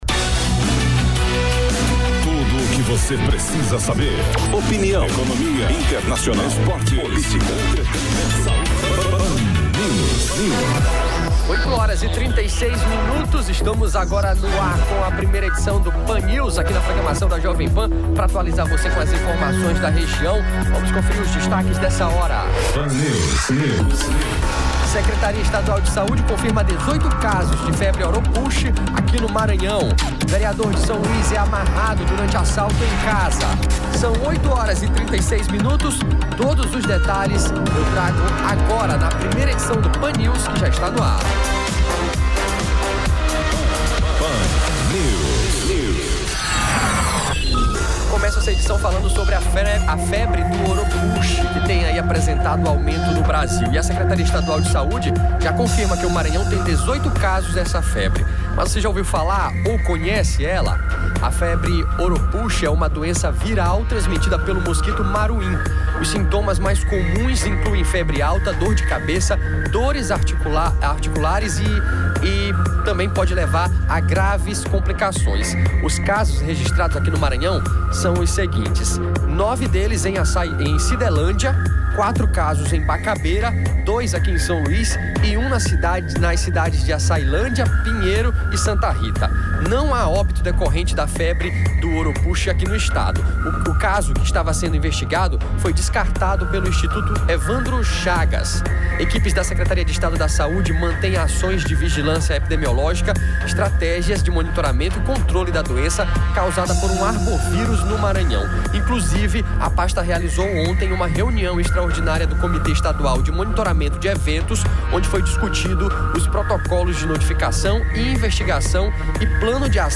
• Ao vivo, as primeiras notícias dessa manhã de terça-feira: Durante perseguição policial, bandidos batem carro na Camboa.